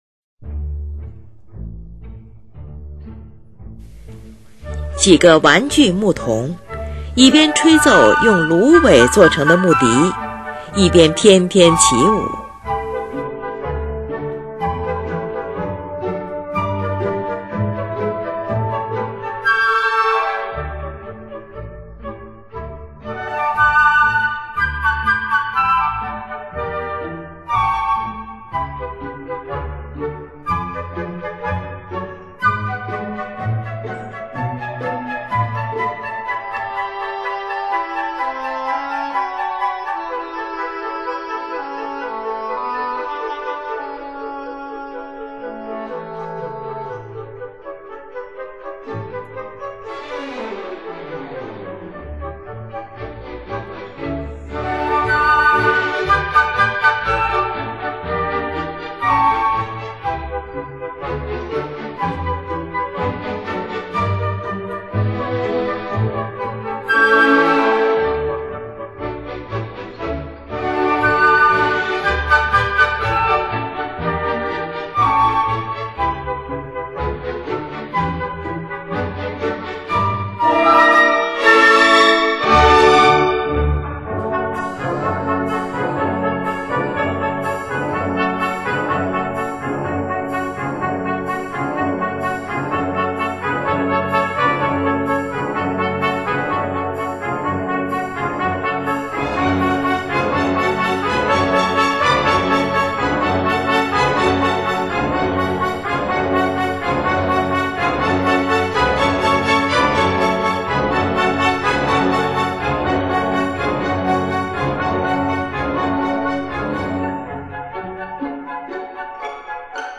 有三支长笛重奏田园般的旋律，轻盈华丽而诱人。